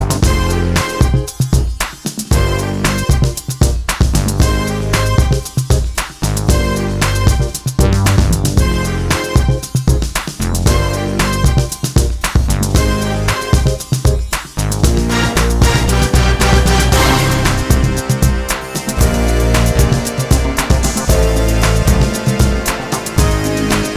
For Solo Male Soundtracks 4:29 Buy £1.50